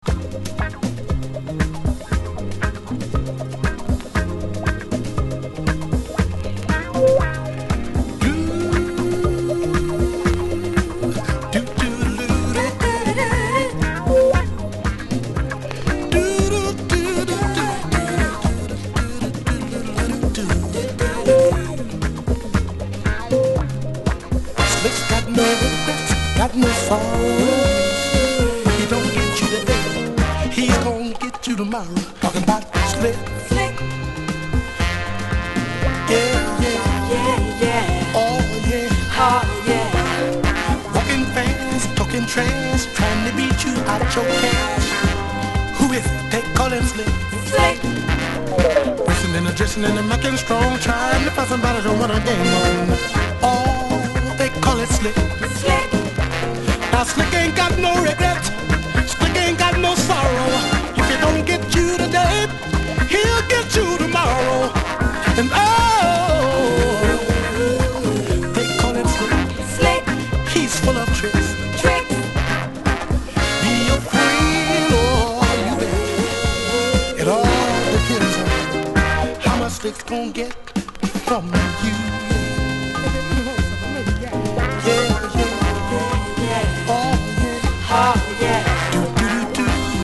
# RARE GROOVE# FUNK / DEEP FUNK# SOUL 45# NORTHERN / MODERN